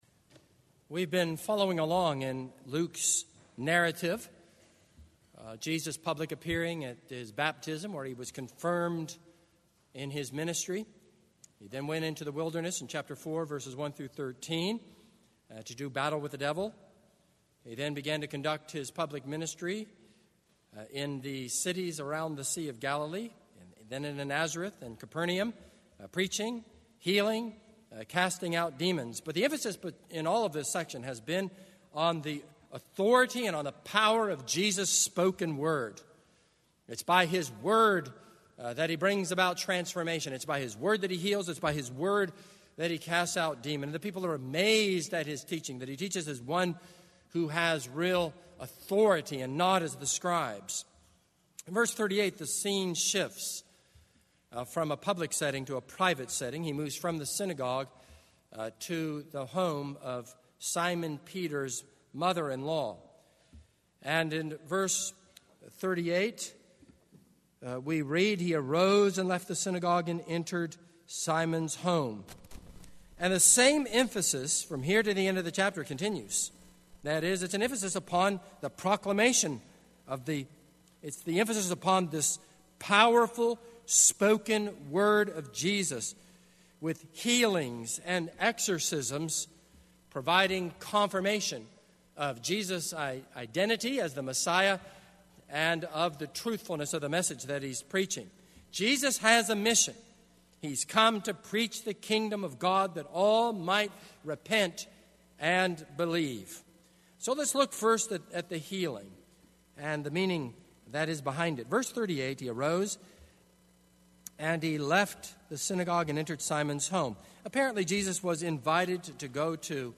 This is a sermon on Luke 4:38-44.